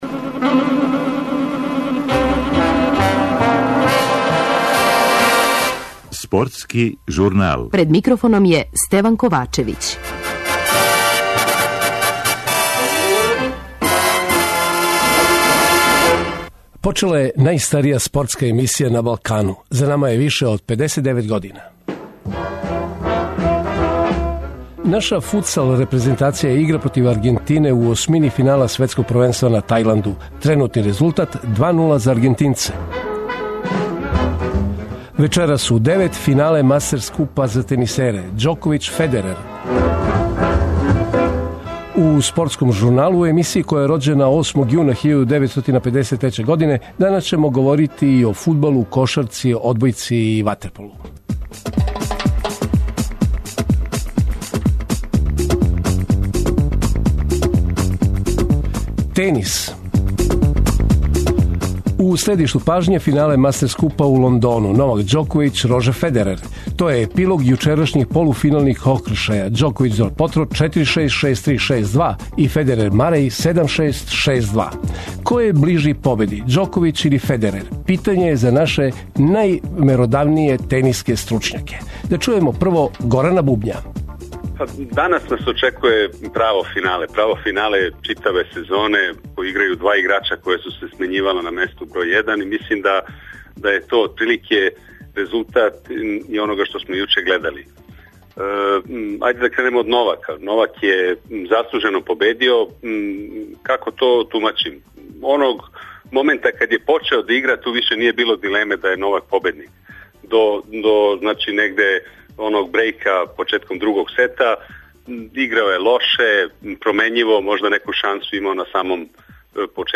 Чућете и извештај са конференције за новинаре из Спортског друштва Црвена звезда, мушког и женског одбојкашког клуба поводом утакмица трећег кола Лиге шампиона.